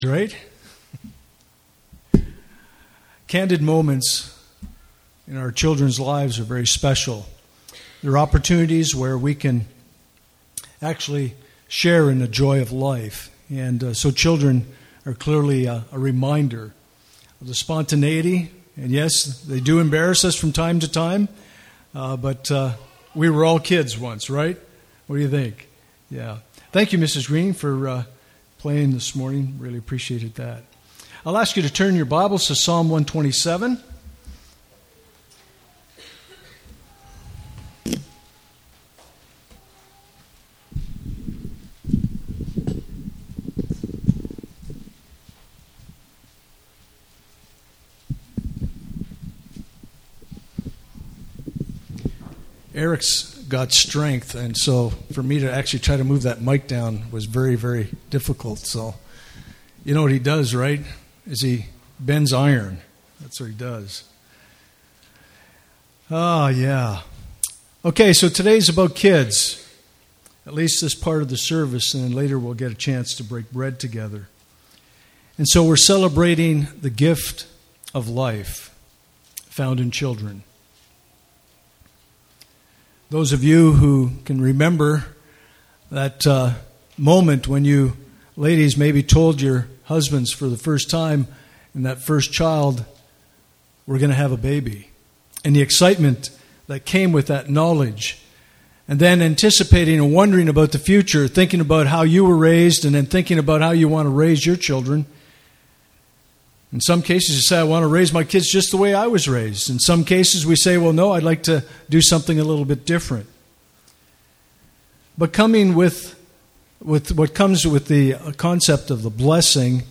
Communion Service
Communion Passage: Psalm 49:1-9 Service Type: Sunday Morning « Baptism Are You a Paul?